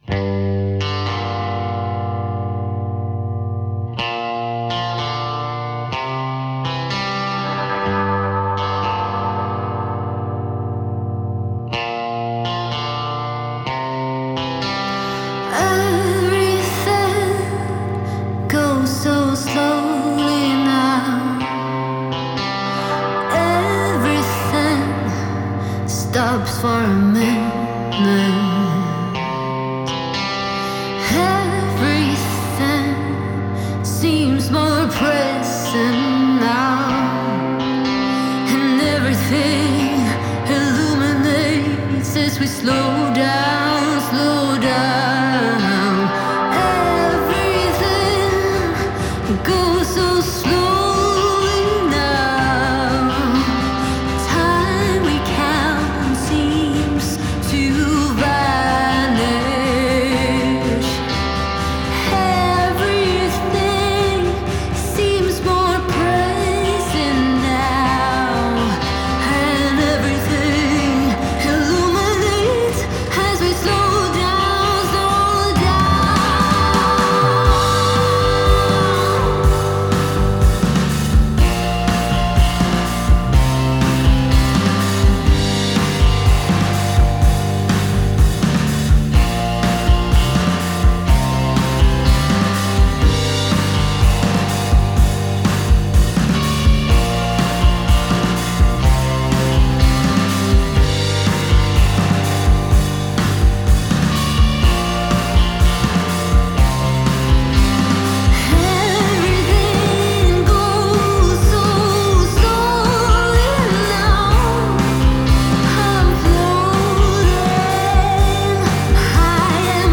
Genre: Indie, Rock, Pop